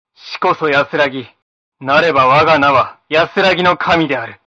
■ボイスサンプル■